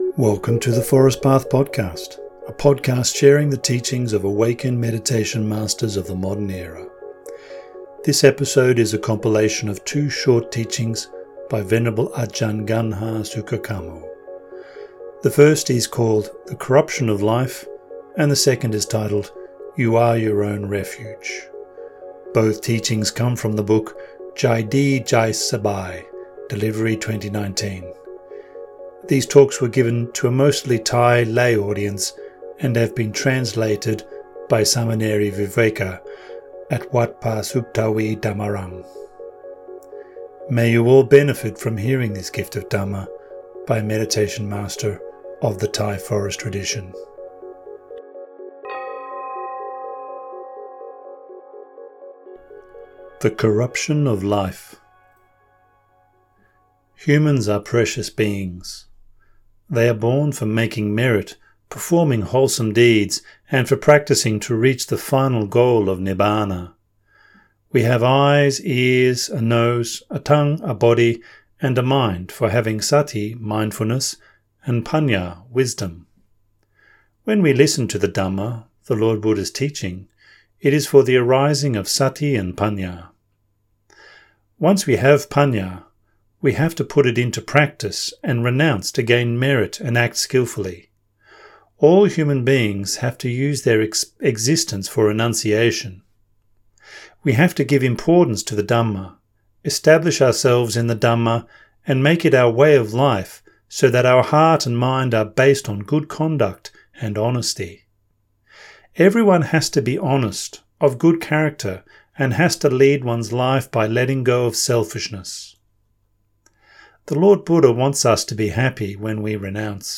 The first is The Corruption of Life and the second is titled You Are Your Own Refuge. Both teachings come from the book Jai Dee - Jai Sabai - Delivery 2019.